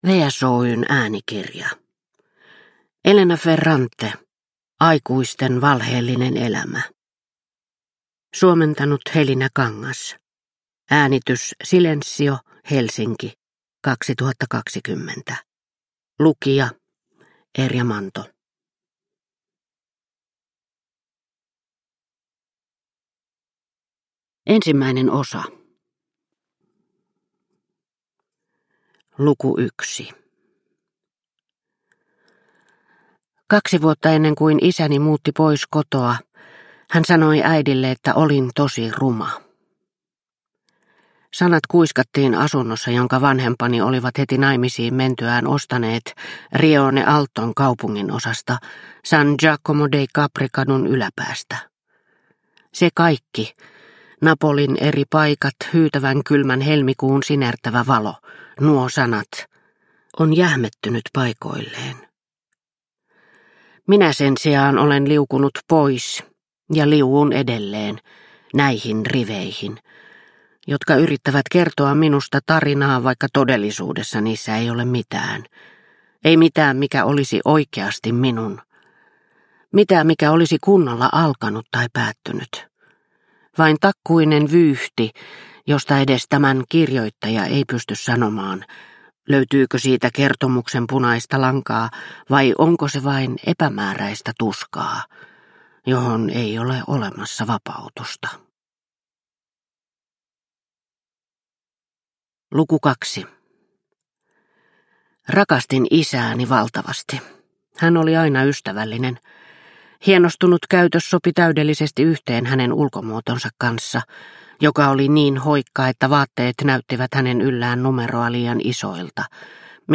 Aikuisten valheellinen elämä – Ljudbok – Laddas ner